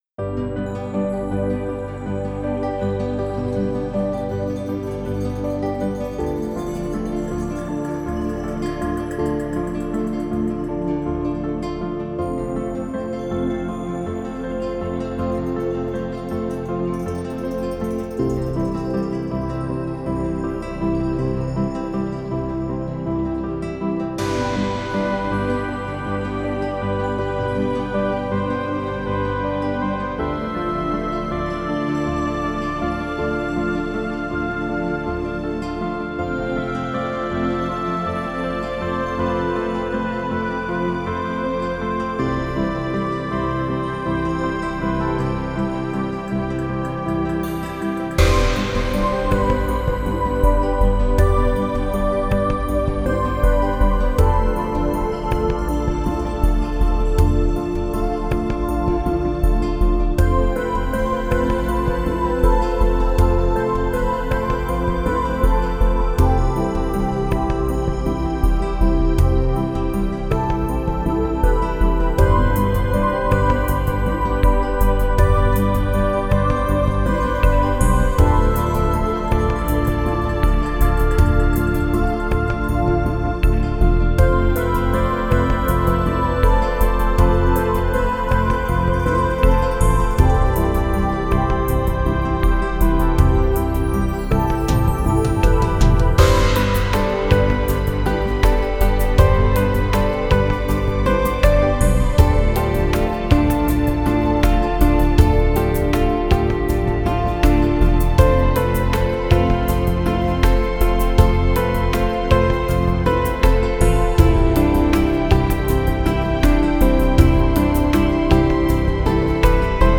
new age